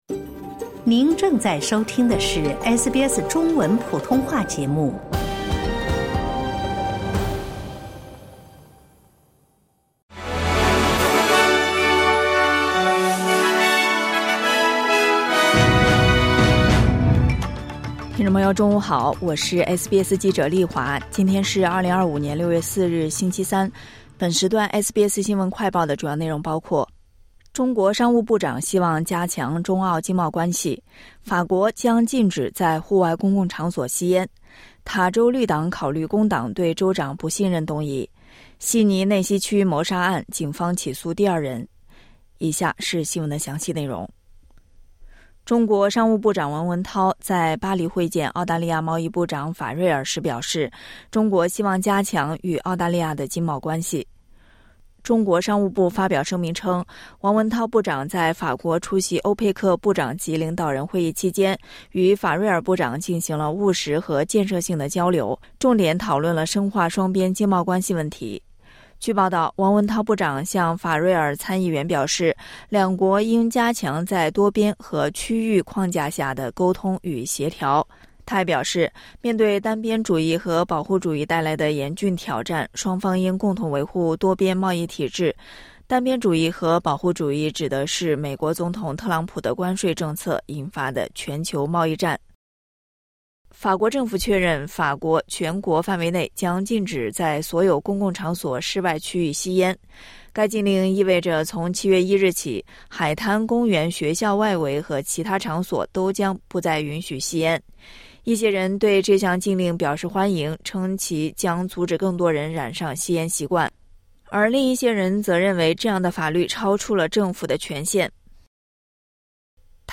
【SBS新闻快报】中国商务部长希望加强中澳经贸关系